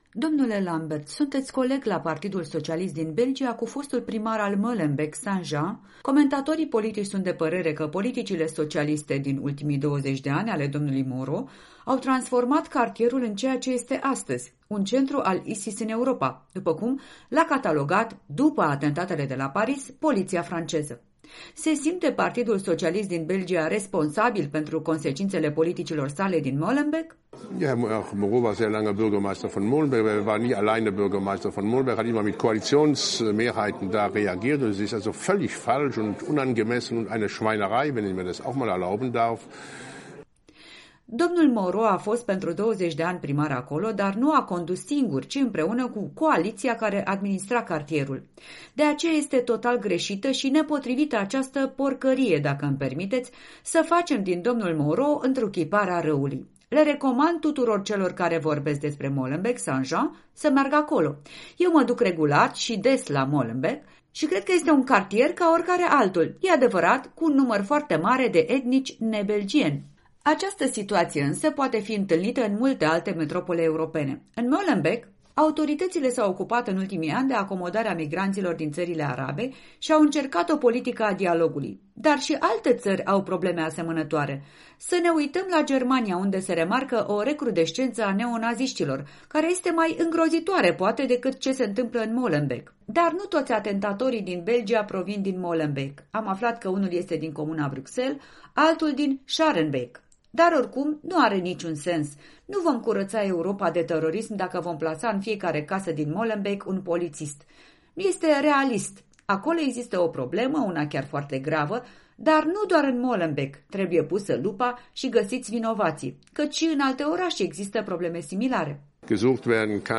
Un interviu cu președintele Parlamentului Comunității Germanofone din Belgia